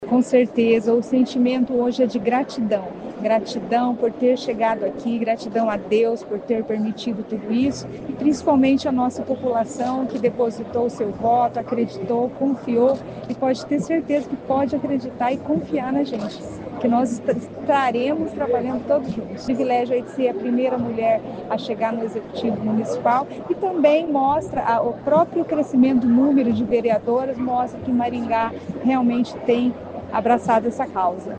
cantou para o público.